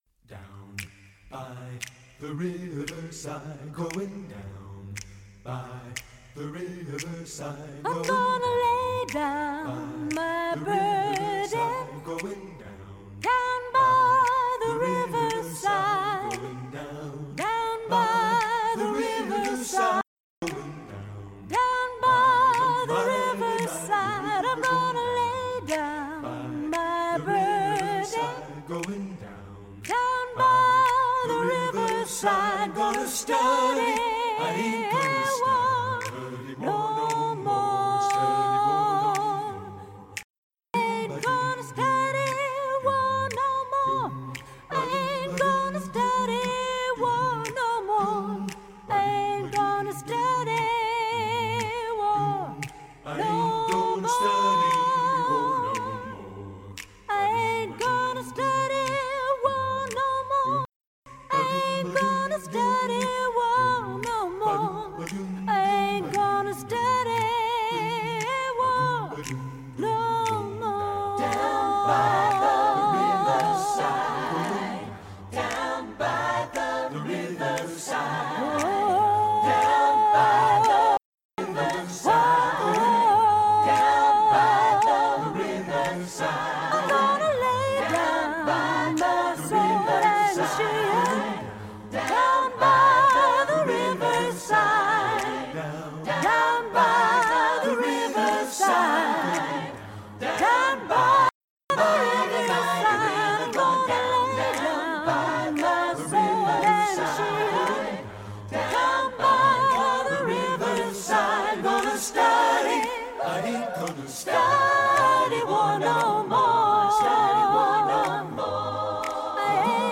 down-by-the-riverside-in-G-a-cappella-hlpc-1994-no-beeps.mp3